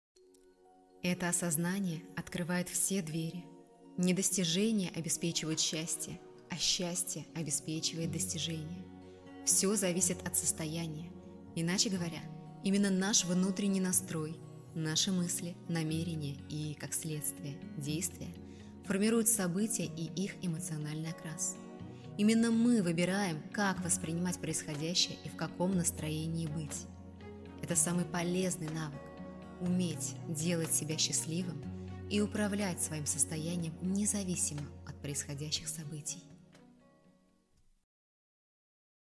Закадровый_мотивация
Жен, Закадровый текст/Молодой
Записываю в изолированной комнате. Микрофон Recording tools MCU-02, окруженный акустическими щитами.